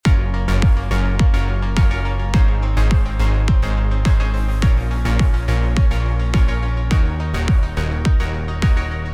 1. Dotted 1/8th
Great for house music, or anything where you want to push the groove forwards.
The dotted 8th creates a syncopated rhythm against the kick drum, and is a staple for dance music of all genres.